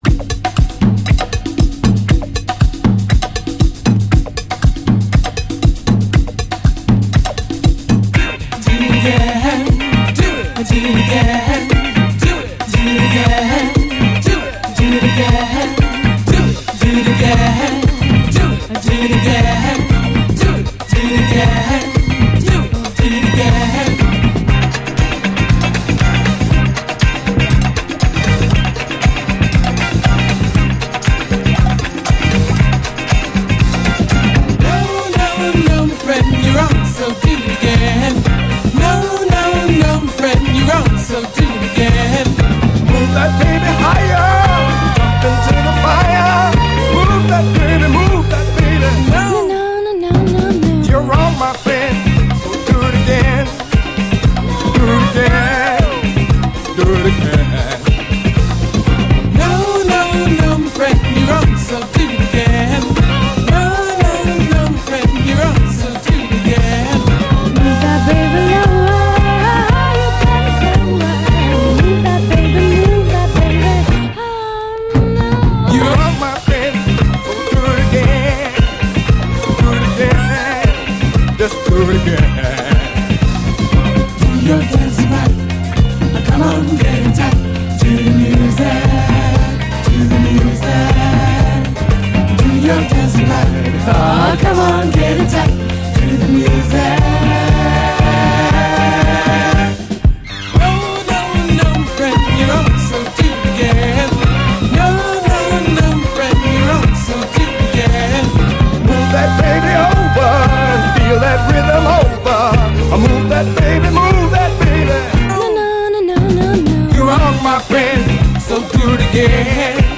アルバム通してお洒落なシンセ・ソウル！